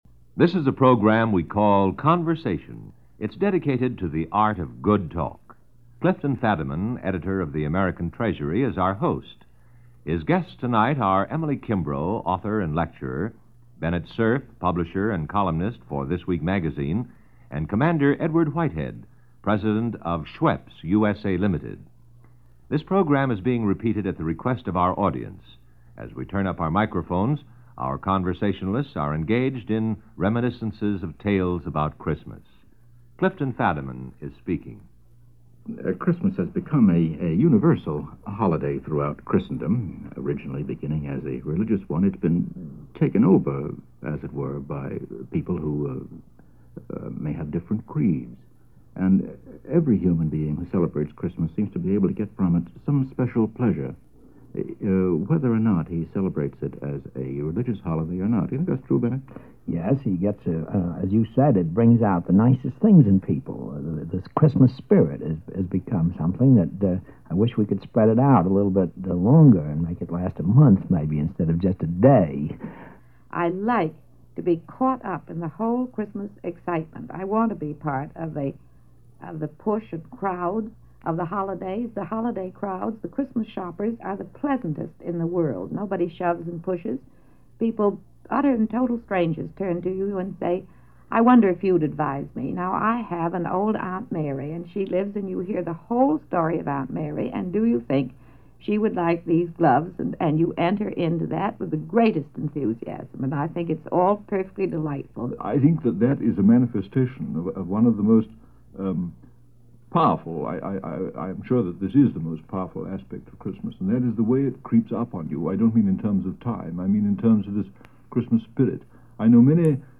Pondering Christmas Past - 1956 - Past Daily Reference Room - NBC Radio Conversation with Clifton Fadiman - December 1956.
Conversation-Christmas-1956.mp3